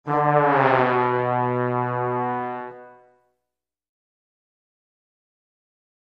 Music Effect; Jazz Trombone Slide Down.